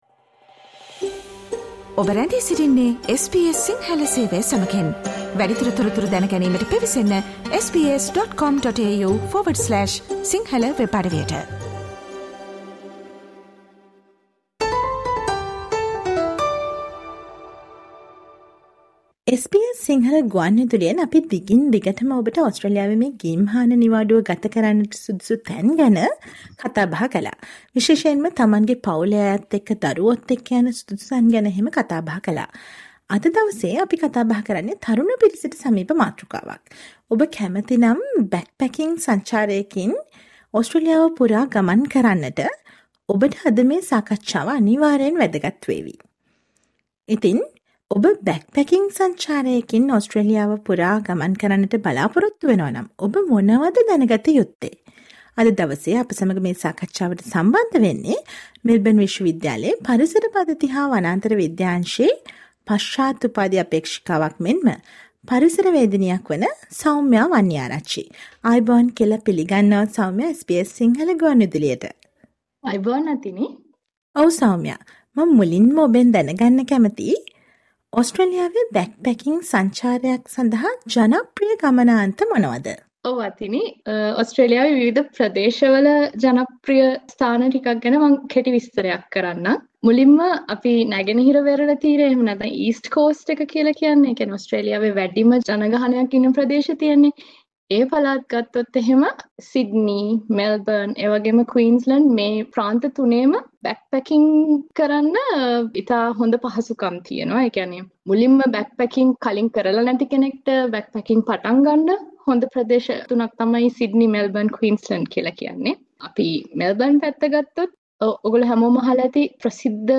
SBS සිංහල ගුවන් විදුලිය ඔබට ගෙන එන සාකච්ඡාව.